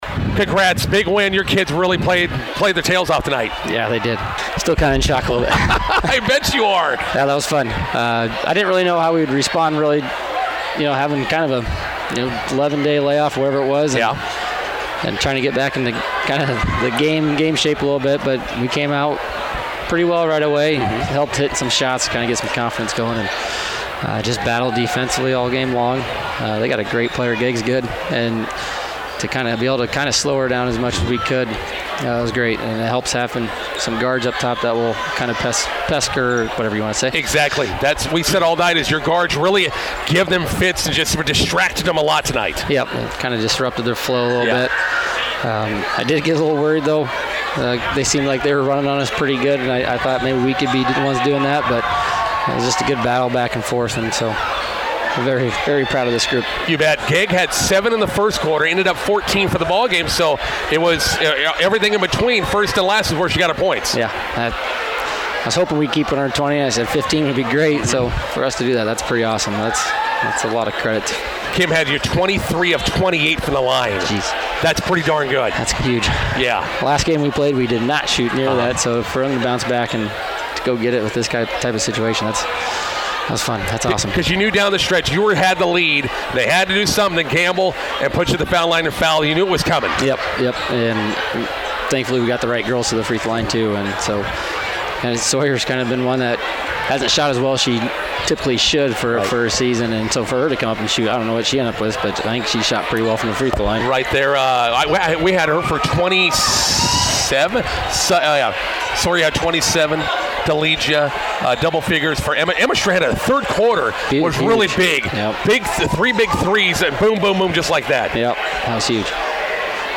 INTERVIEW: Arapahoe girls punch Class D1 state basketball tournament ticket with win at Shelton.